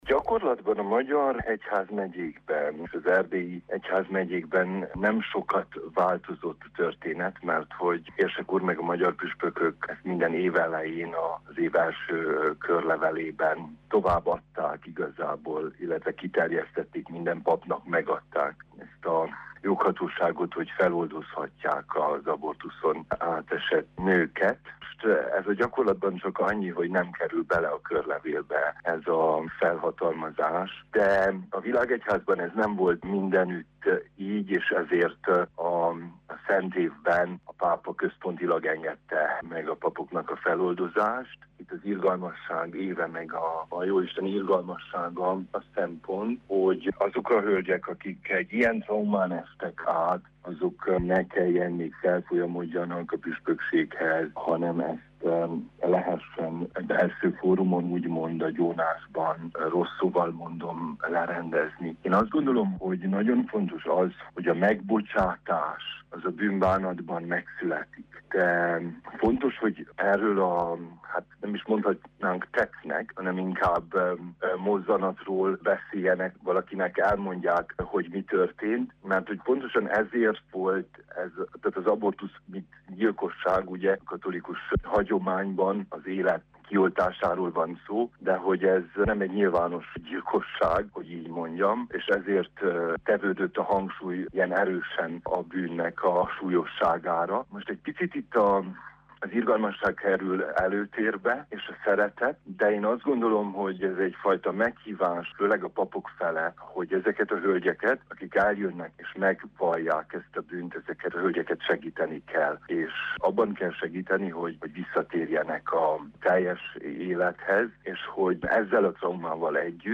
Az interjút